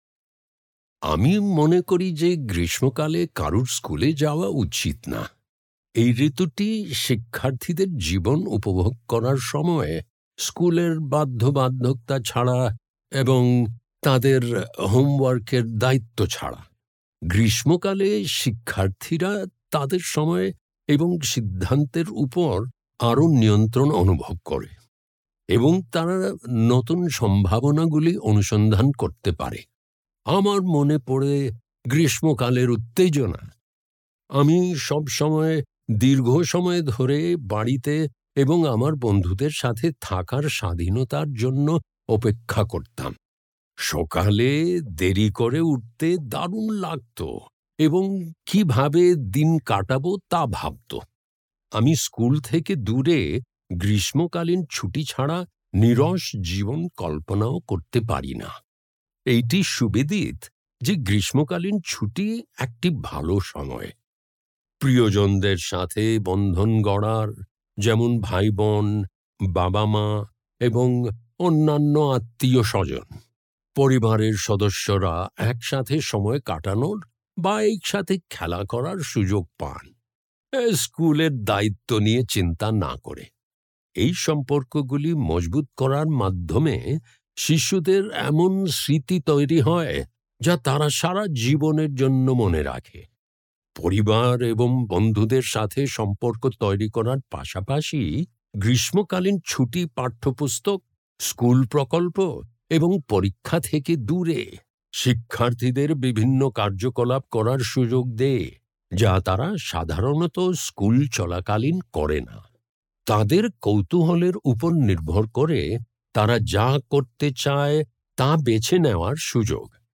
Presentational Speaking: Bengali
[Note: In the transcript below, ellipses indicate that the speaker paused.]